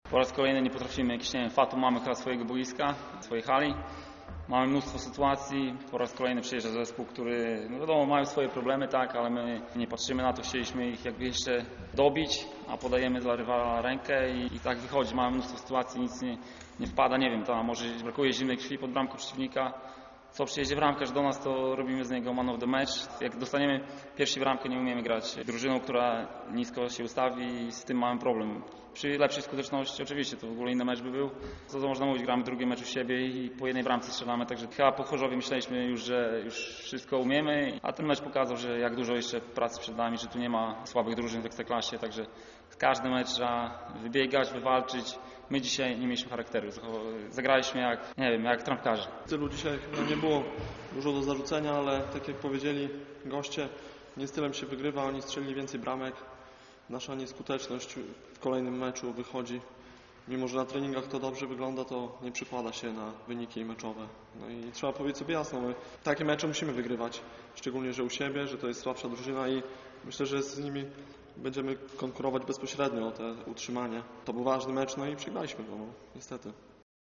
Z zawodnikami MOKS Słoneczny Stok Białystok rozmawia